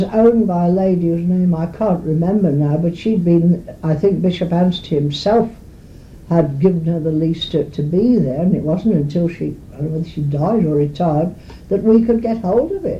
The Oral and Pictorial Records Programme (OPReP)